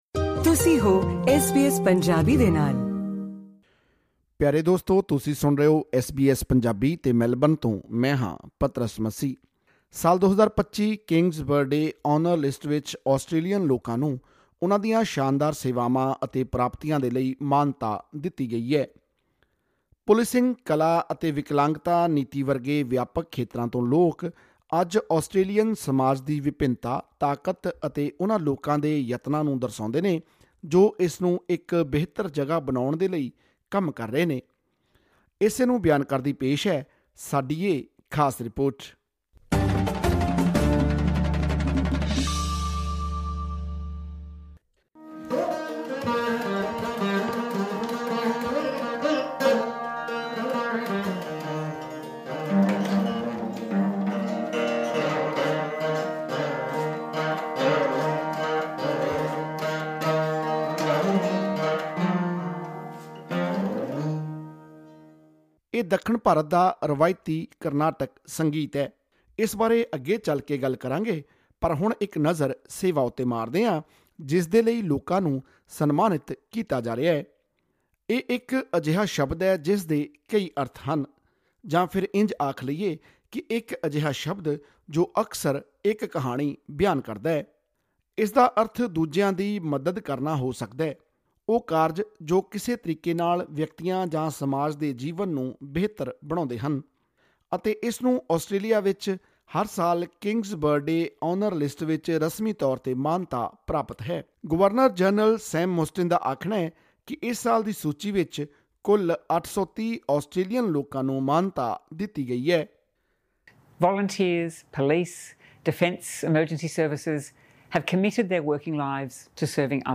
ਹੋਰ ਵੇਰਵੇ ਲਈ ਸੁਣੋ ਇਹ ਆਡੀਓ ਰਿਪੋਰਟ...